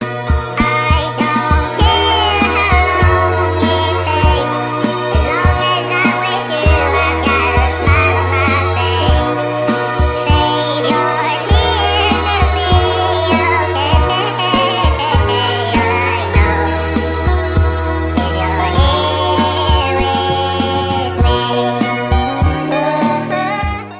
Sweet melody of love and desire to be close